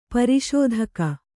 ♪ pari śodhaka